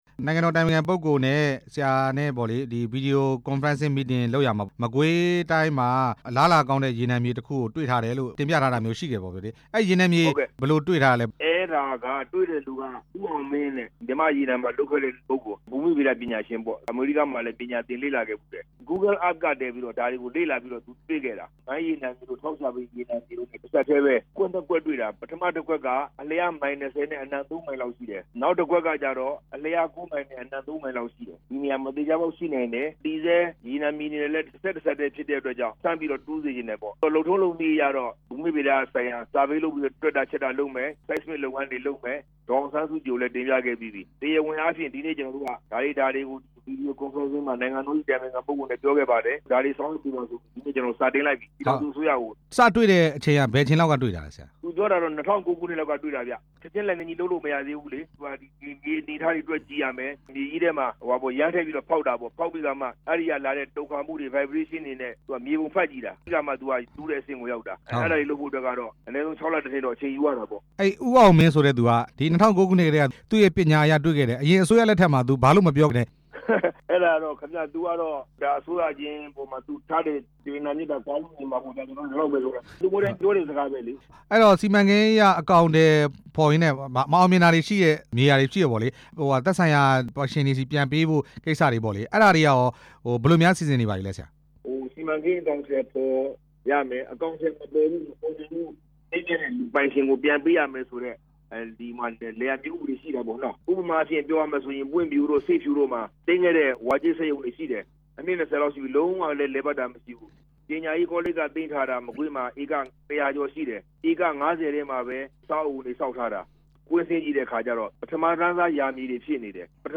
မကွေးတိုင်းမှာ ရေနံကြော တွေ့ရှိတဲ့အကြောင်း မေးမြန်းချက်